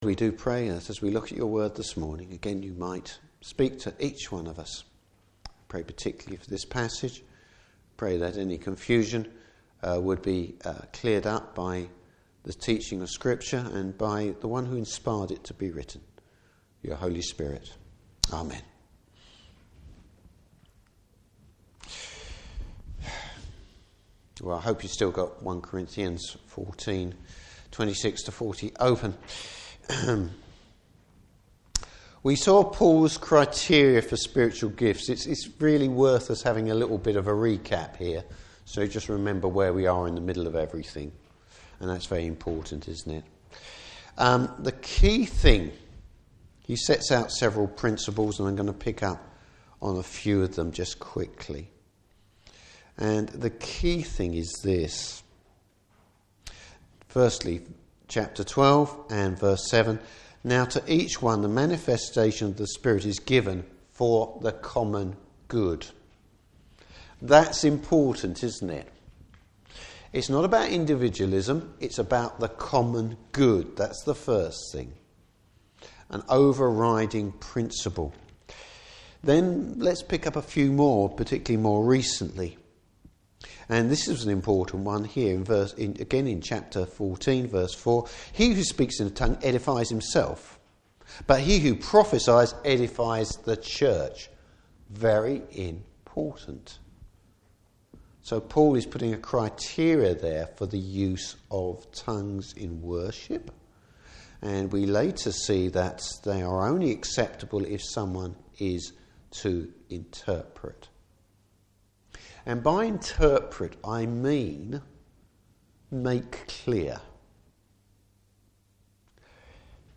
Service Type: Morning Service Orderly worship.